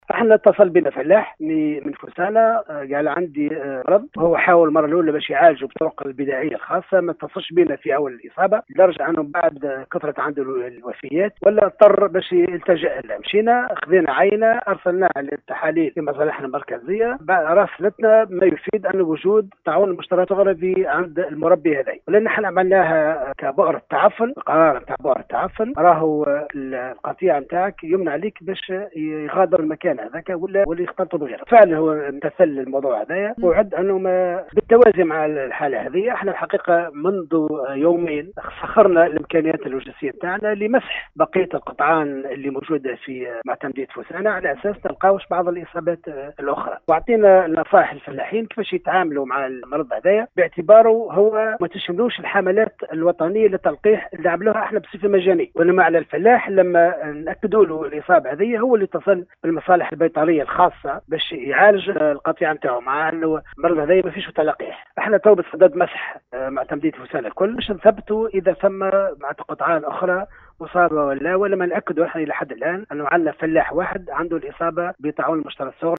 نفى  مندوب الفلاحة بالقصرين محمد المحمدي في تصريح لراديو السليوم اف ام صباح اليوم 3 سبتمبر ، استشراء مرض طاعون المجترات الصغرى بقطعان الأغنام بمعتمديه فوسانة  على عكس ما تم تداوله على عديد المواقع الاجتماعية و الإخبارية ، مؤكدا انه تم تسجيل بؤرة وحيدة لدى فلاح بنفس الجهة .